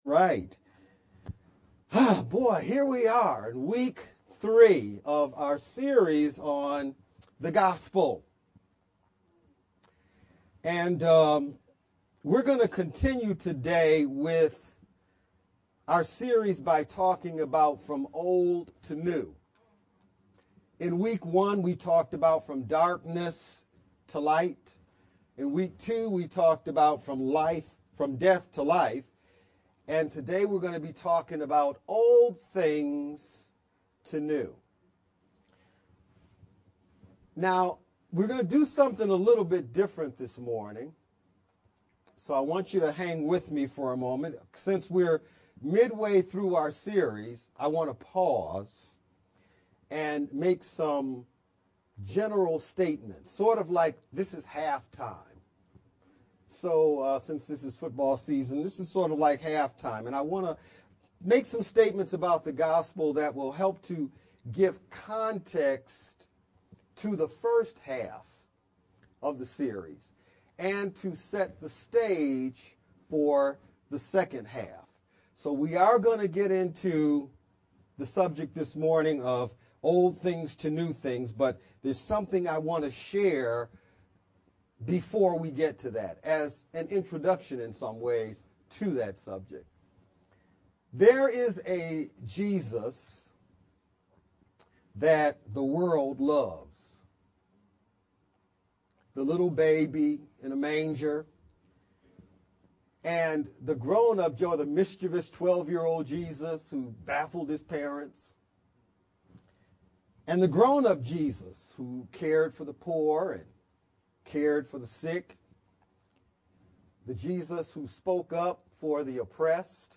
Dec-18th-VBCC-Service-sermon-only-CD.mp3